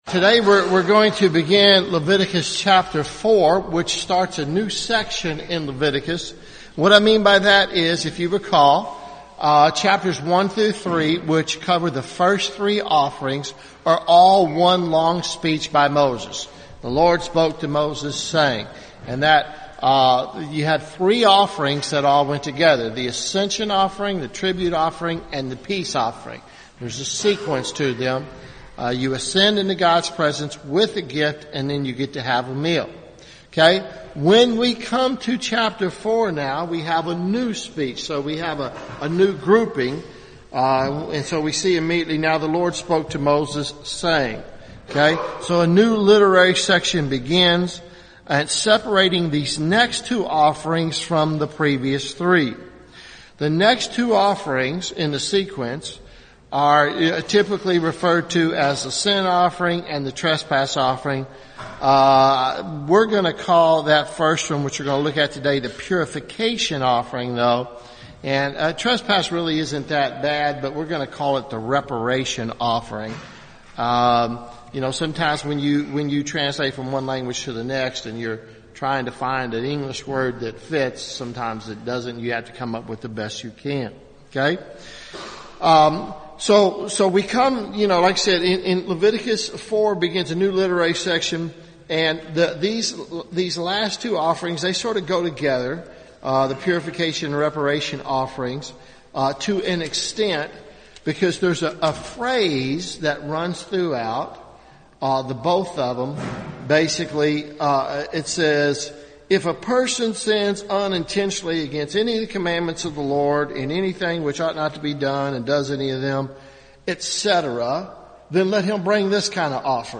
Leviticus Part 13 Leviticus Sunday school series